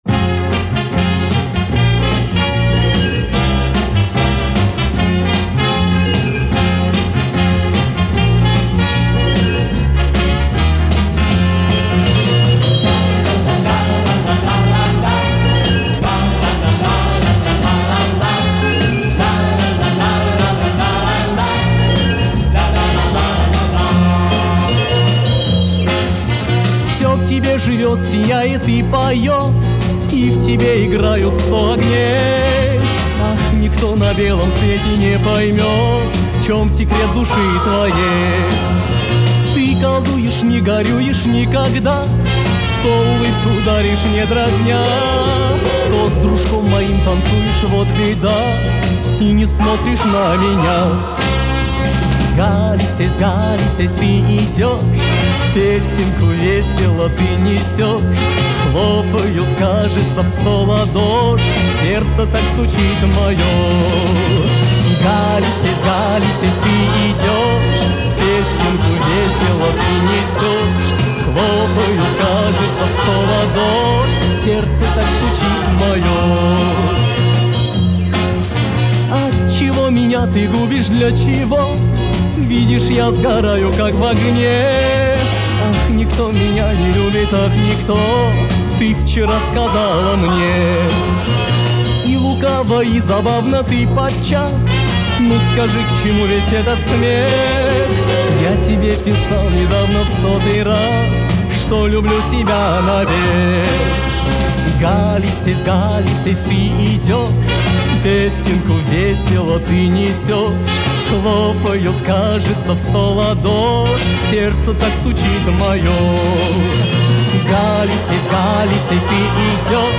Формат - Пластинки, 7", 33 ⅓ RPM, Mono
Звук отредактирован и восстановлен